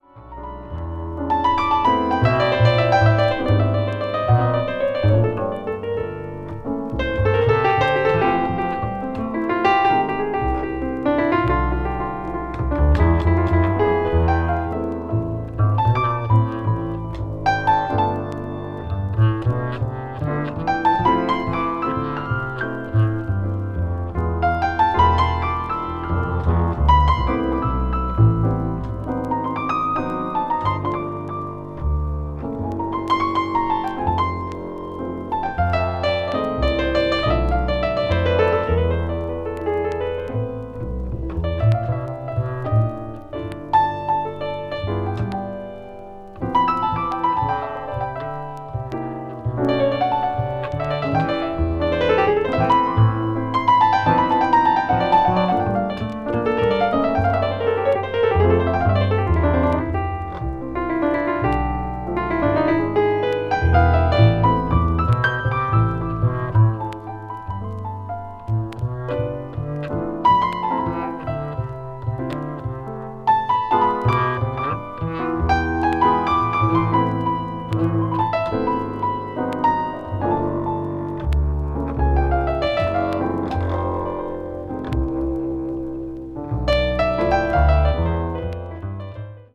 contemporary jazz